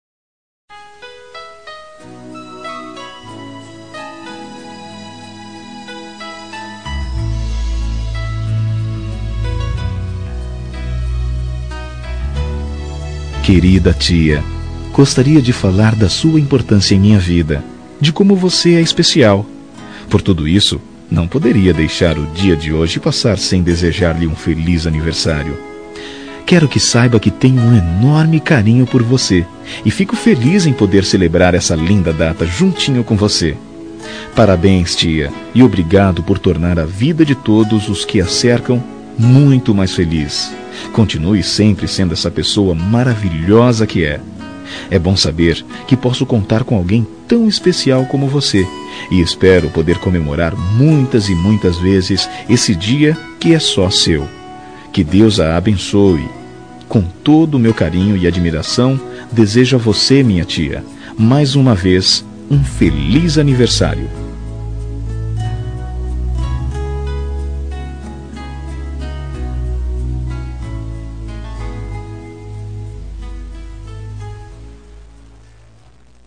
Telemensagem Aniversário de Tia – Voz Masculina – Cód: 2018